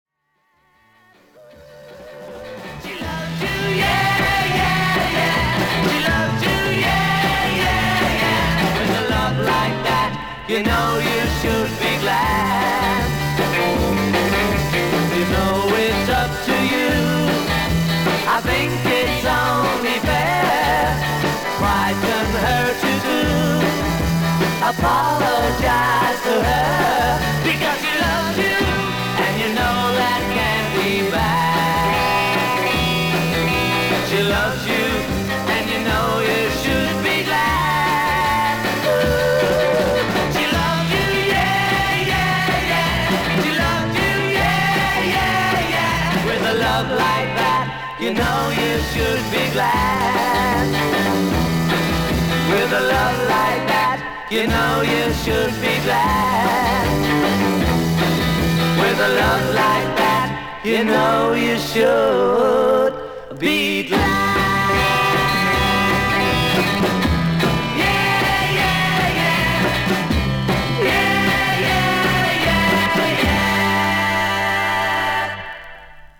A1後半に溝に沿って10cmほどキズがありますがノイズは感じませんでした。
全体的なグレードはVG+～VG++:少々軽いパチノイズの箇所あり。少々サーフィス・ノイズあり。クリアな音です。